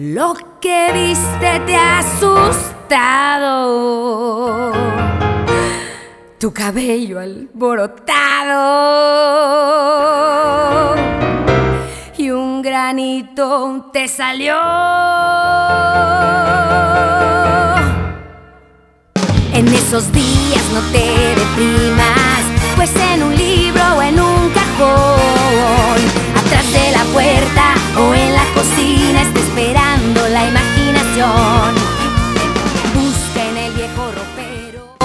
In 2009, she released another new children's album.